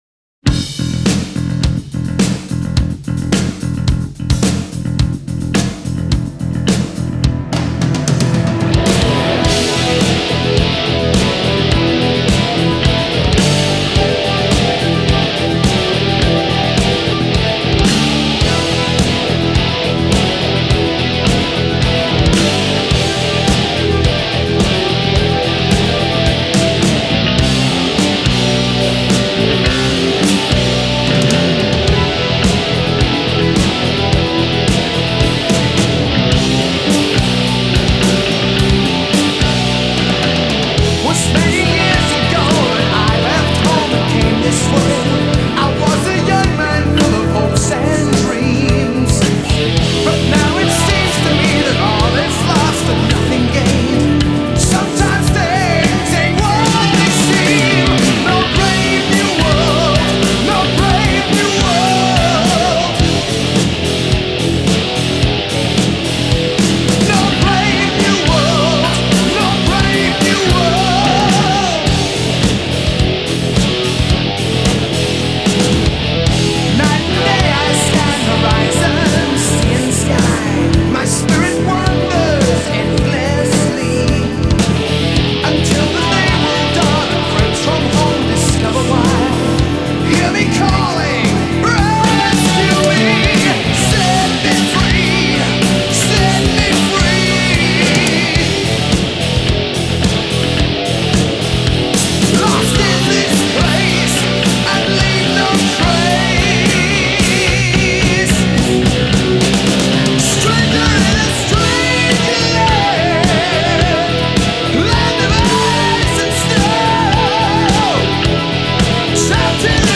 گیتار سولوی این آهنگ (4:26-3:19) و گیتار ریفش وحشتناک عالیه